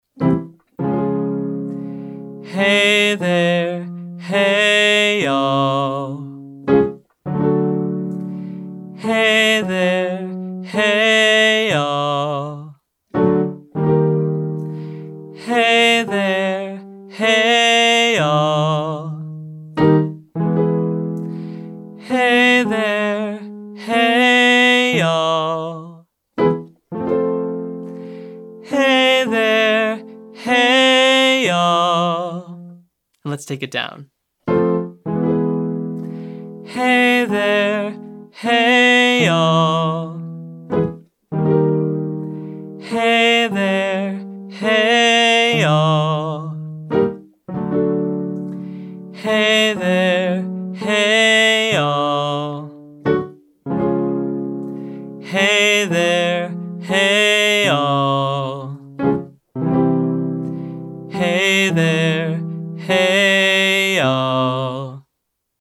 Short Daily Warmup